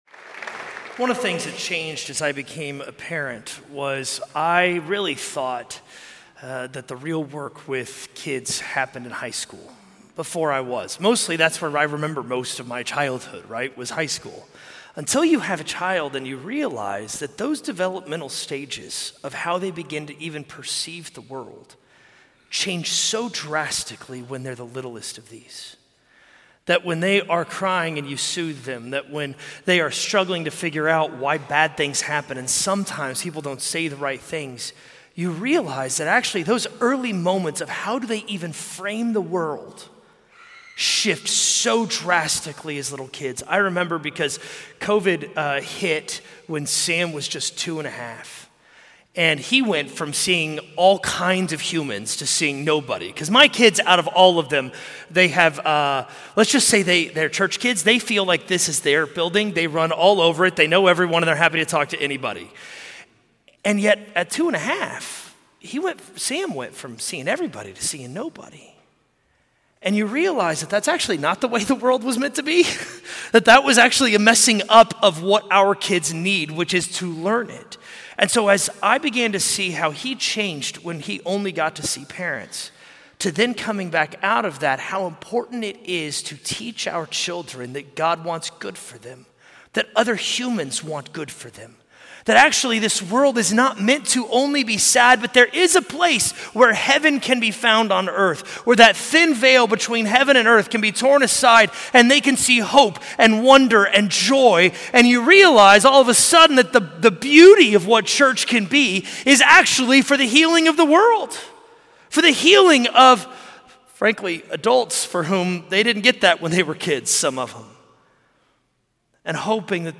A message from the series "Jesus Is Good News."